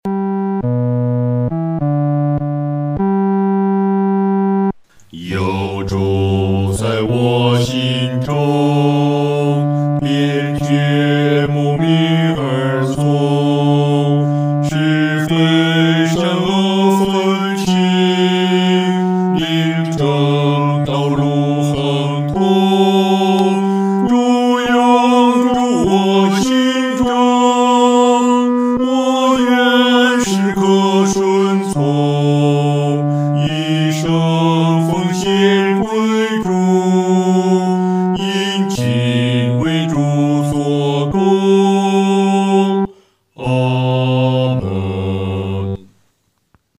合唱
男低
诗班在二次创作这首诗歌时，要清楚这首诗歌音乐表情是亲切、温存地。